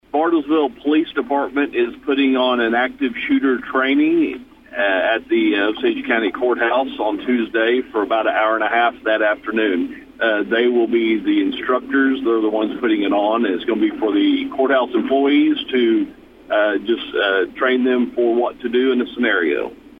District Two Commissioner Steve Talburt said this was the first of its kind and Sheriff Bart Perrier elaborates